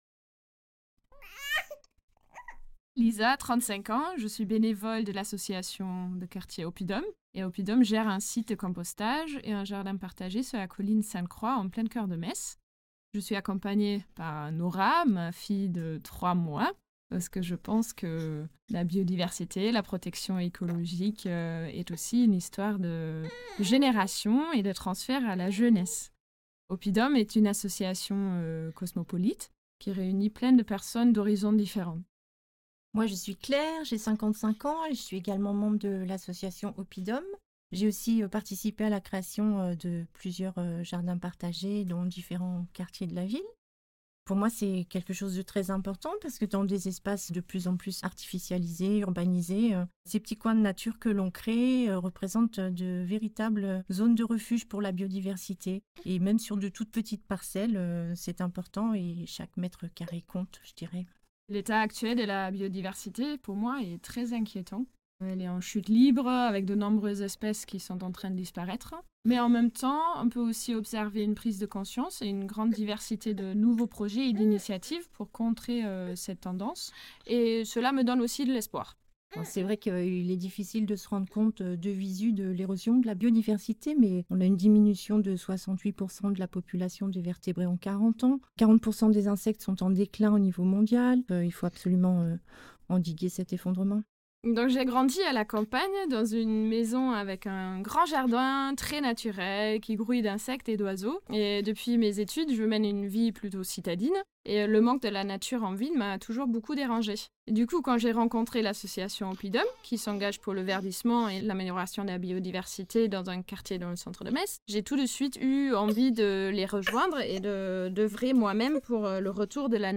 Paroles de citoyens, militants et scientifiques
Le Pavillon de la Biodiversité propose aux visiteurs d'écouter plusieurs prises de paroles : citoyens, militants et scientifiques parlent de la biodiversité et de la nature. Ces interview sont également accessibles en ligne.